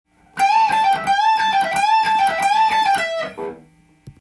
オリジナルギターtab譜　key Am
フレーズは全てペンタトニックスケールで構成されています。